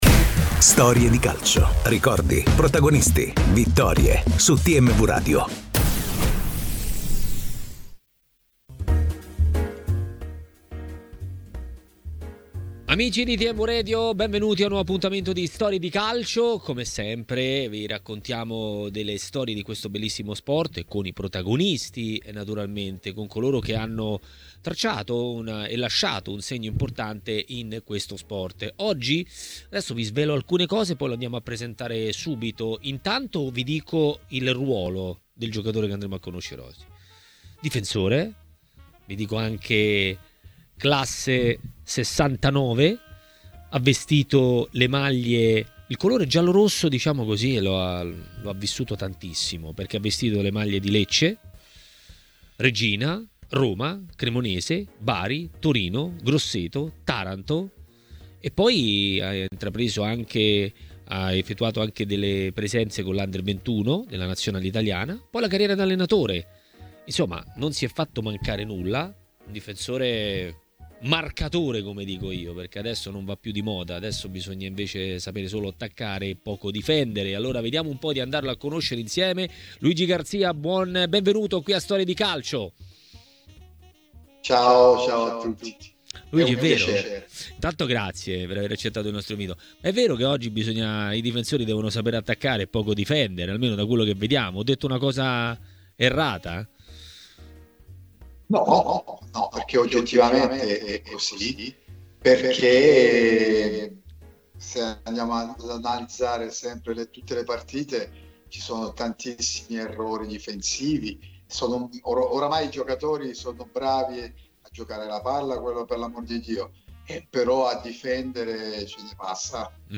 A Storie di Calcio, trasmissione di TMW Radio, protagonista è stato il difensore Luigi Garzya, classe '69, che ha vissuto una vita tra Serie A e B, specialmente con i colori giallorossi.